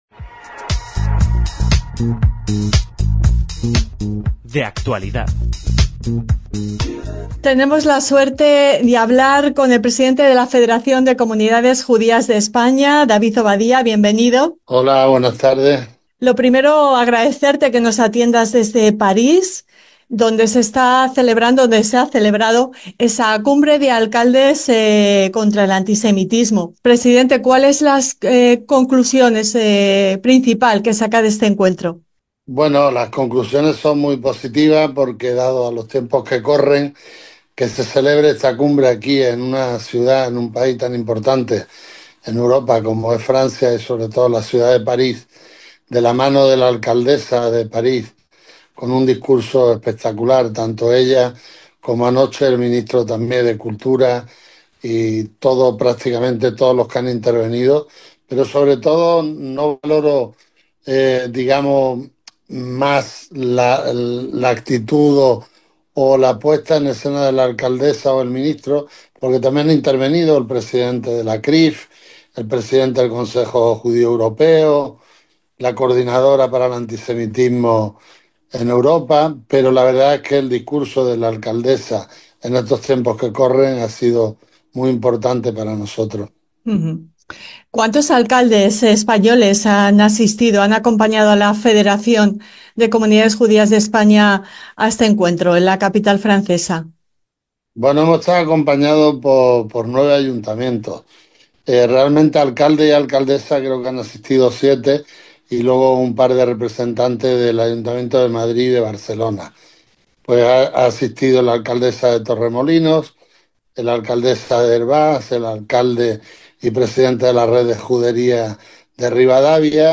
Desde la Cumbre de Alcaldes contra el Antisemitismo de París 2025